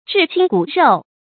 至親骨肉 注音： ㄓㄧˋ ㄑㄧㄣ ㄍㄨˇ ㄖㄡˋ 讀音讀法： 意思解釋： 至親：關系最近的親戚；骨肉：指父母兄弟子女等親人。